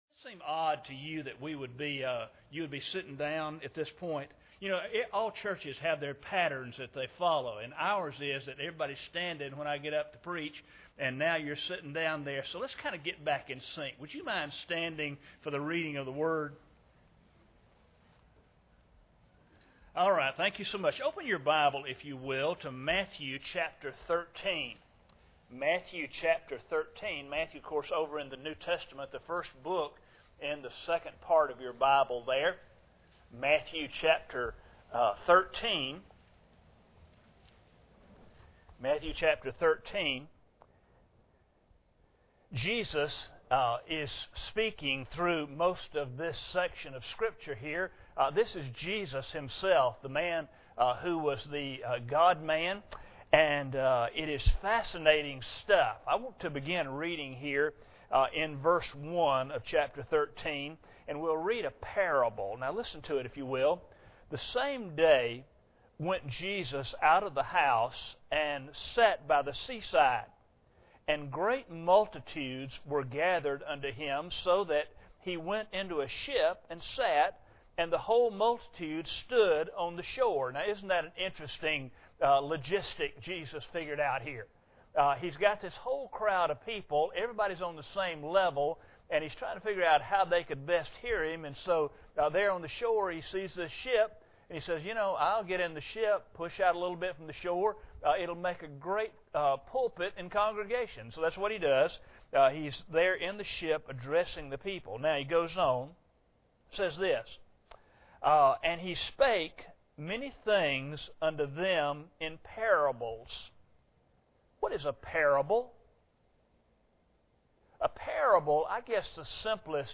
Matthew 13:1-9 Service Type: Sunday Morning Bible Text